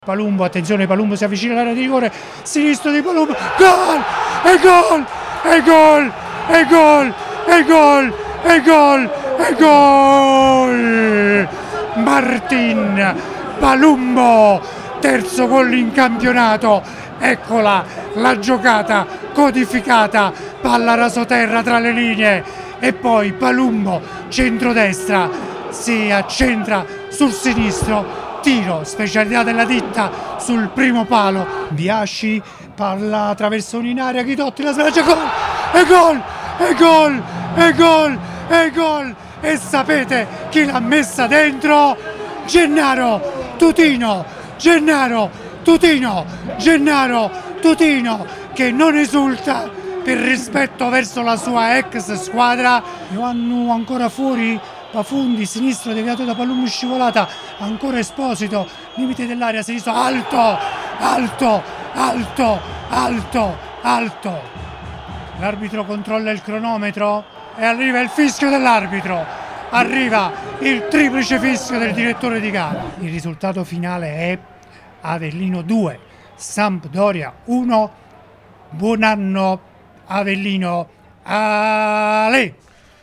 Avellino-Sampdoria 2-1, le emozioni con la radiocronaca